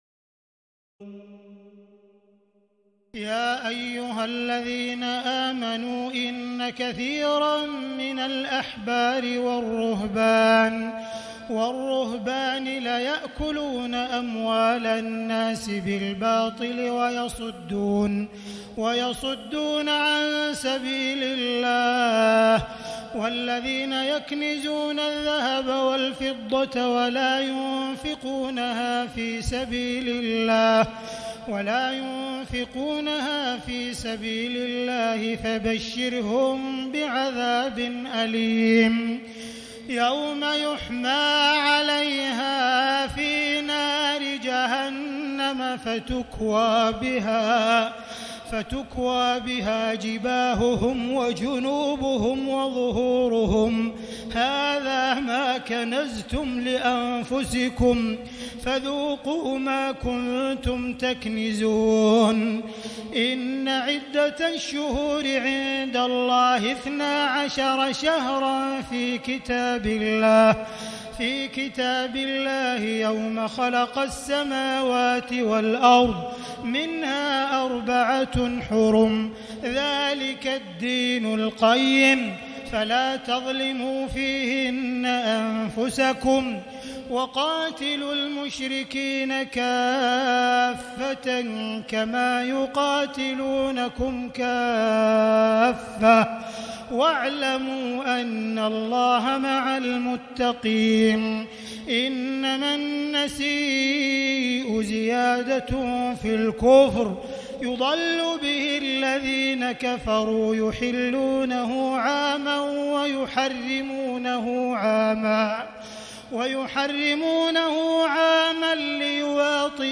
تراويح الليلة التاسعة رمضان 1439هـ من سورة التوبة (34-93) Taraweeh 9 st night Ramadan 1439H from Surah At-Tawba > تراويح الحرم المكي عام 1439 🕋 > التراويح - تلاوات الحرمين